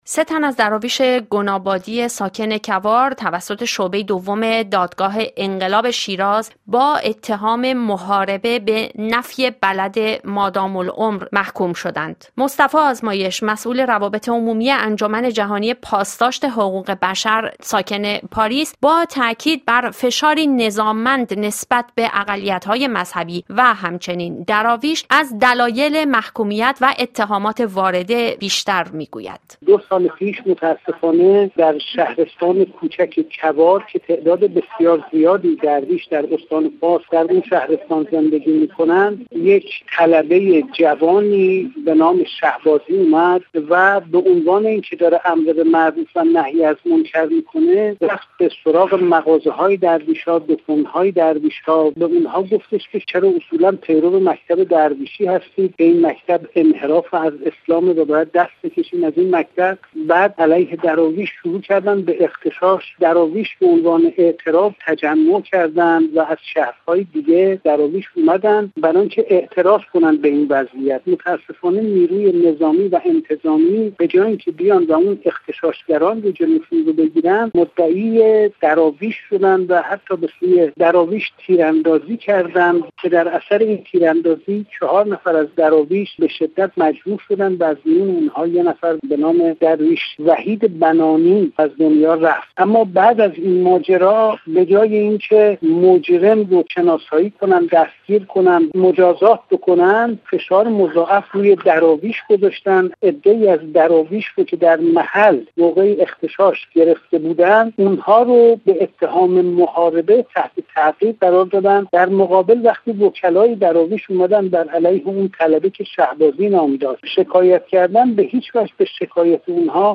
در گفت‌وگو با رادیوی بین المللی فرانسه